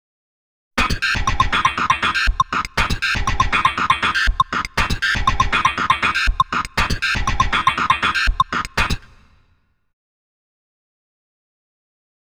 Index of /90_sSampleCDs/Inspiration_Zone/rhythmic loops
05_wavesequence_7_OS.wav